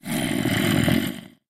sounds / mob / zombie / say1.mp3